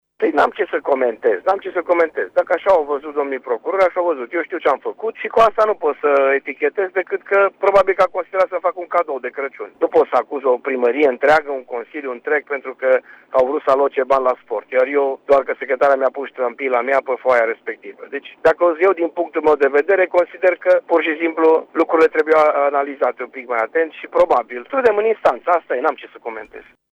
Primarul Dorin Florea a declarat pentru RTM că nu se consideră vinovat în acest caz şi spune că lasă instanţa de judecată să se pronunţe: